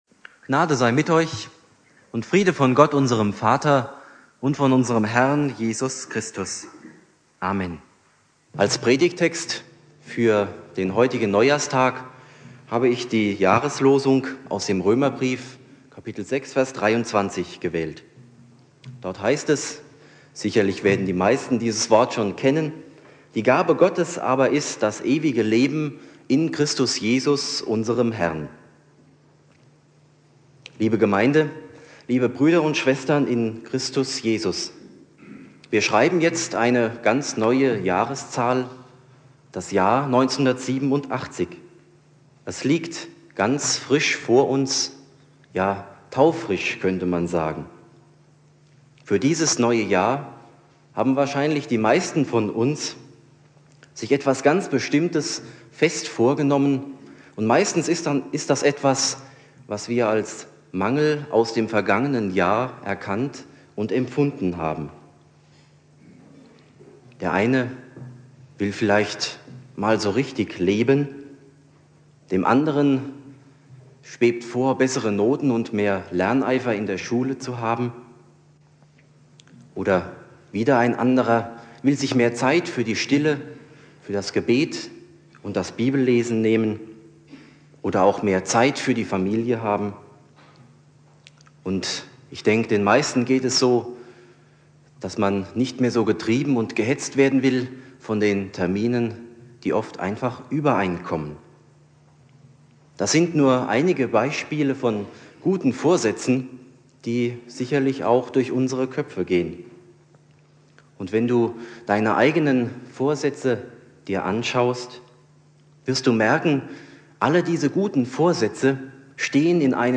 Predigt
Neujahr Prediger